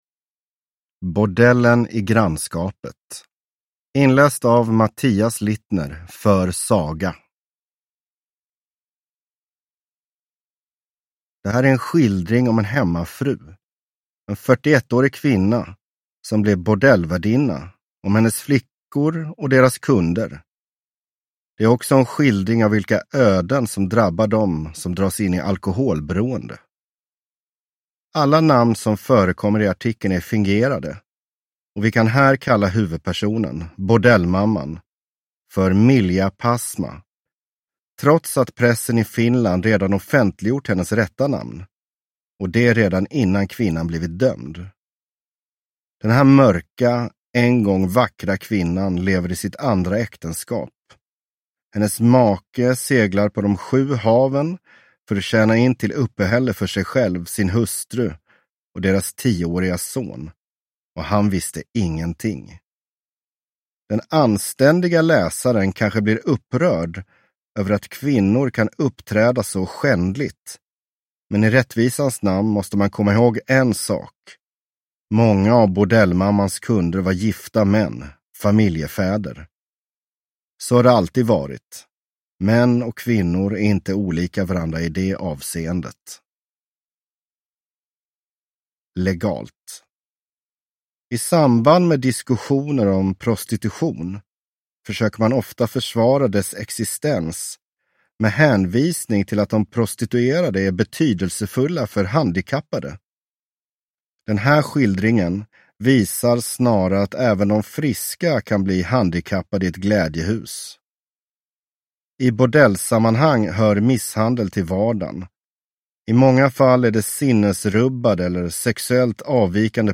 Bordellen i grannskapet (ljudbok) av Svenska Polisidrottsförlaget